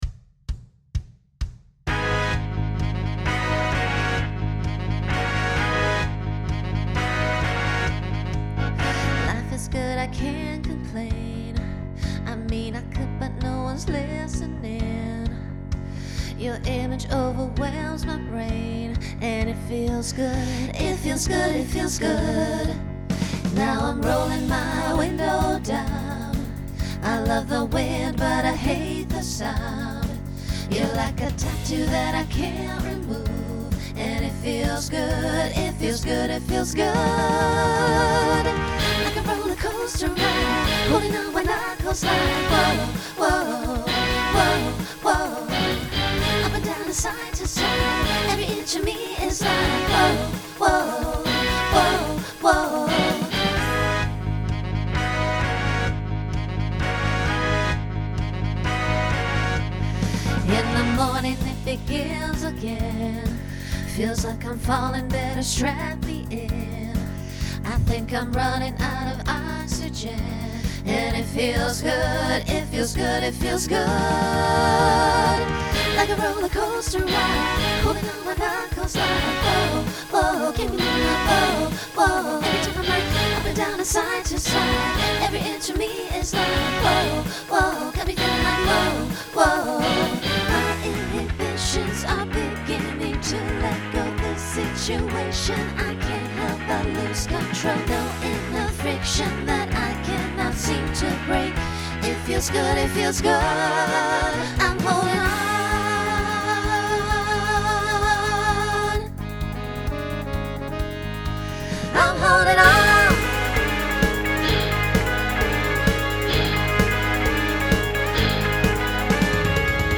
New SSA voicing for 2023.